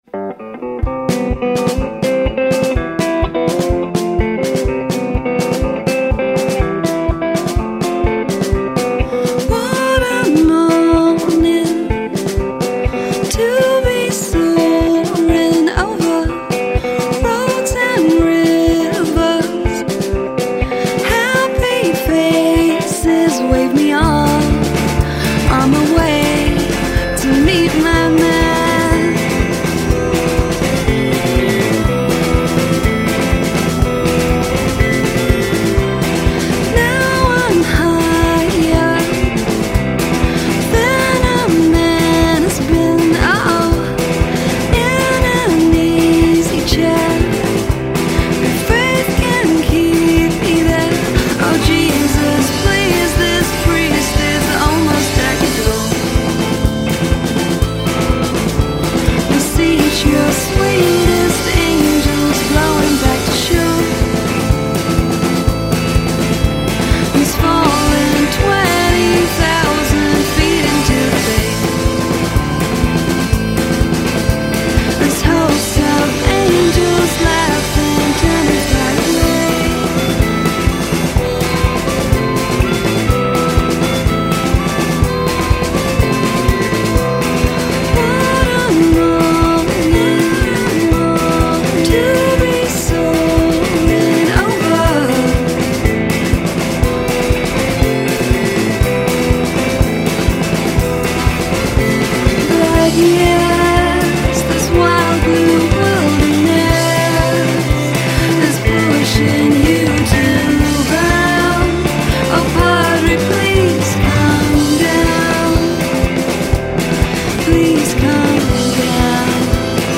vocals/guitar
bass
drums